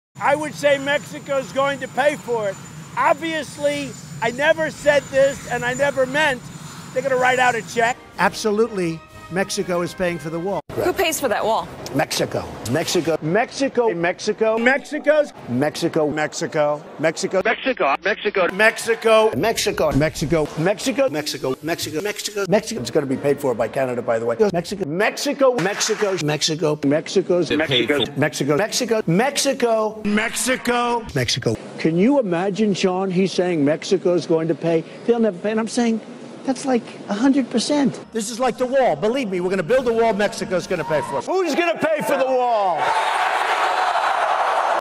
meme
Donald Trump - Mexico's Paying For the Wall [Montage]